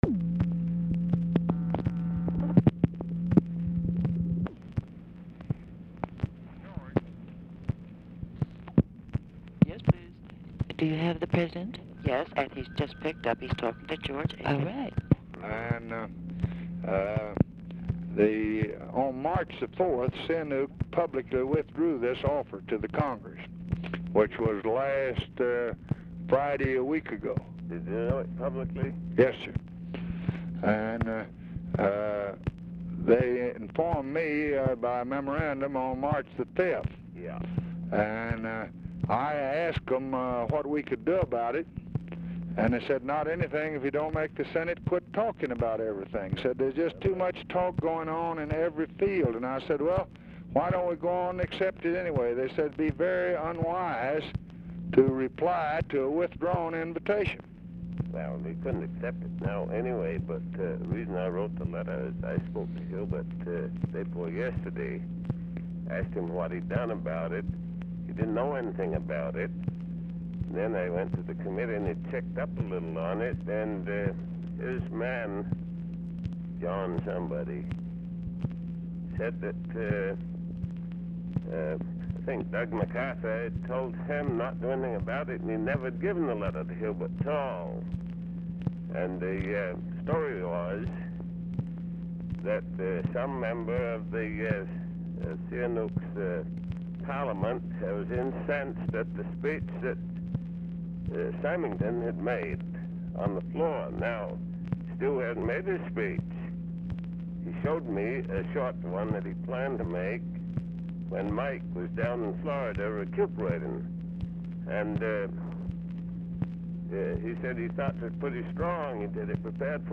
OFFICE SECRETARY, TELEPHONE OPERATOR BRIEFLY INTERRUPT AIKEN AT BEGINNING OF CALL; BACKGROUND HUM
Format Dictation belt